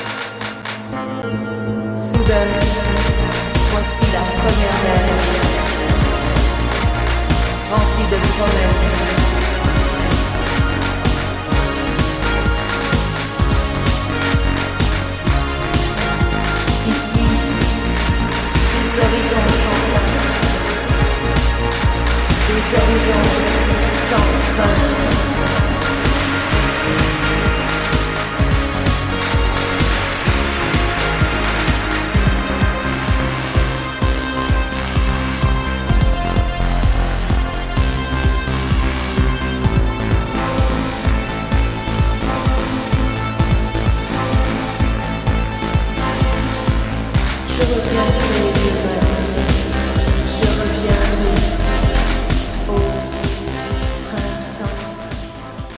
Canadian minimal synth duo